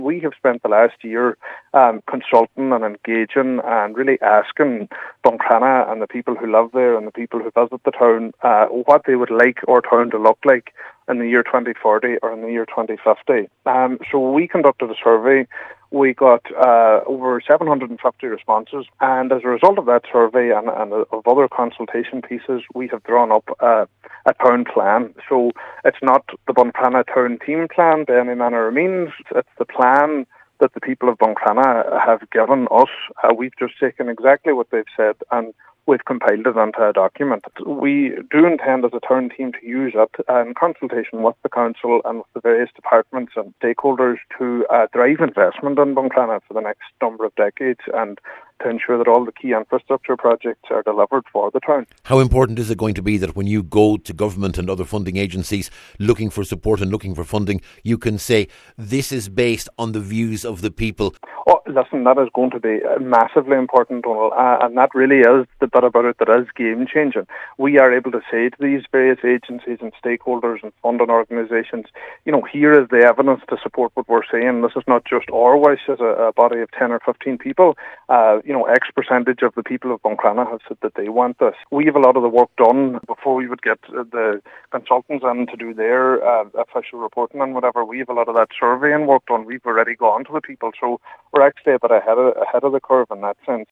Cllr Fionán Bradley says the people of Buncrana have been vital to the plan: